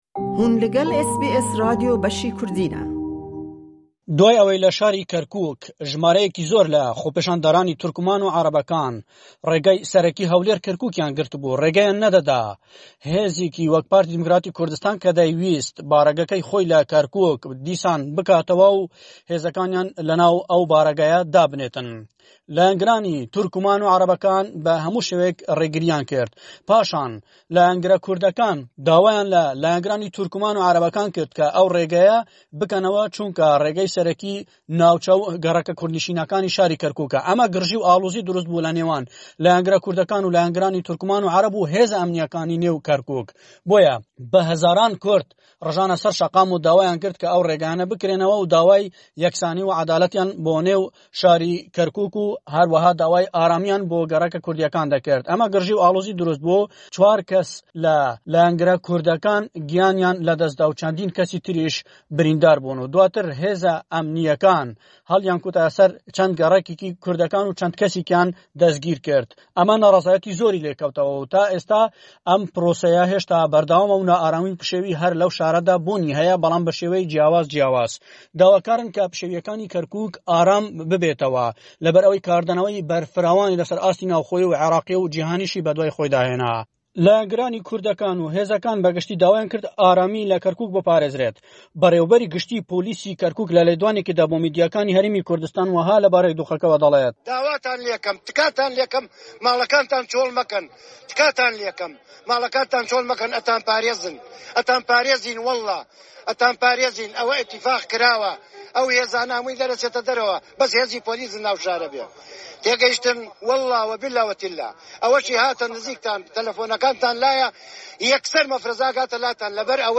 Ew mijarana û mijarên din jî di raportê de hene.